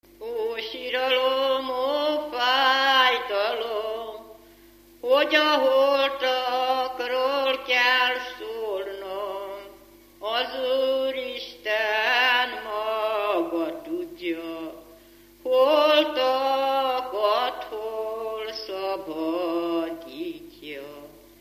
Alföld - Bács-Bodrog vm. - Jánoshalma
ének
Stílus: 4. Sirató stílusú dallamok